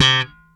C3 3 F.BASS.wav